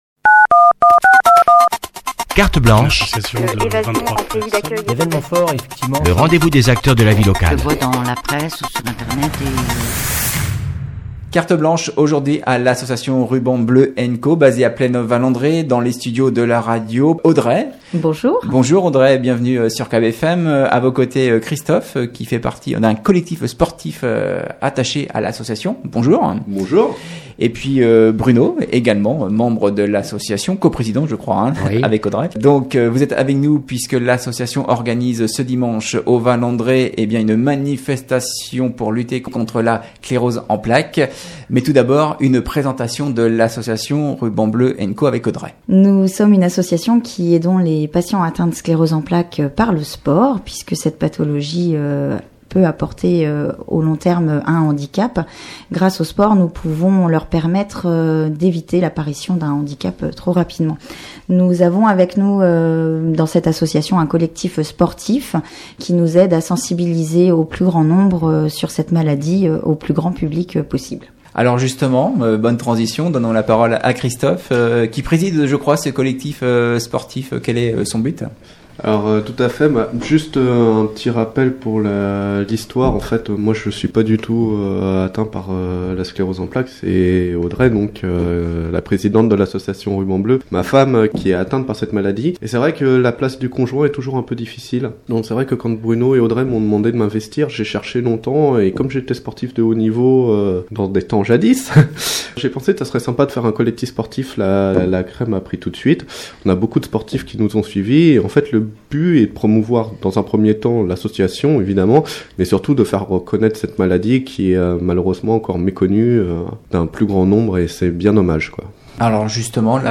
sont venus dans nos studios présenter la manifestation